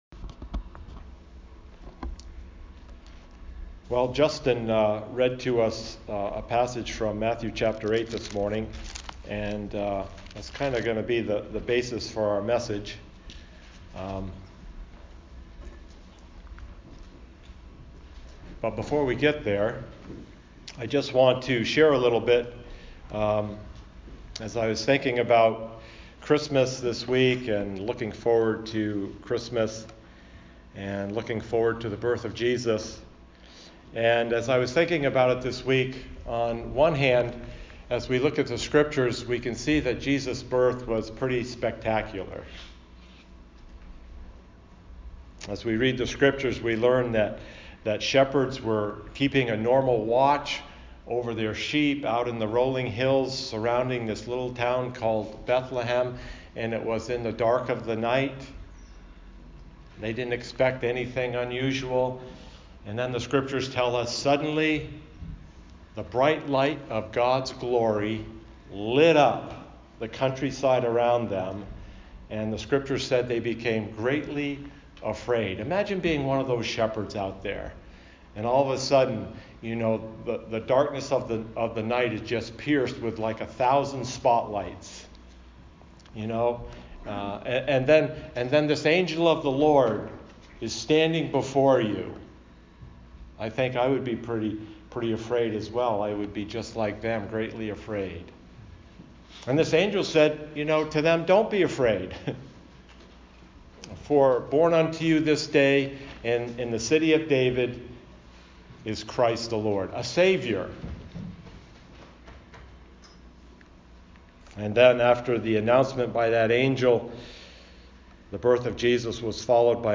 Sermons | Crossroads Church